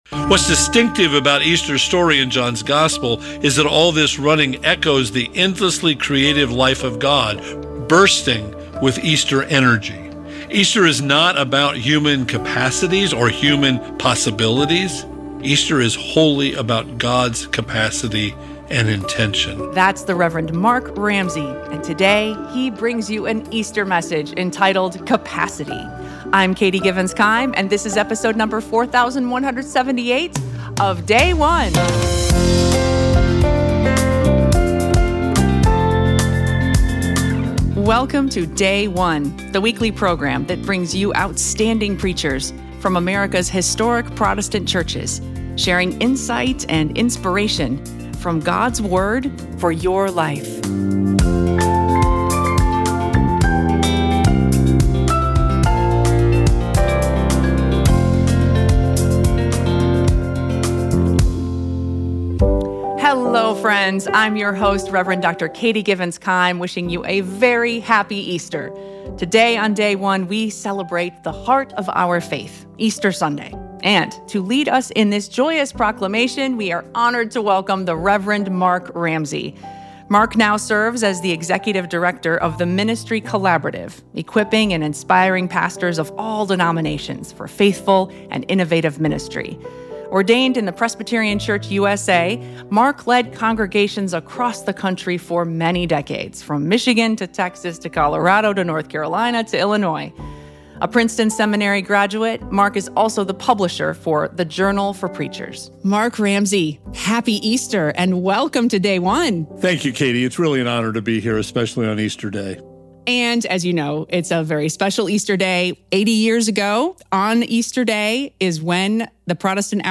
Easter Sunday – Year C John 20:1-18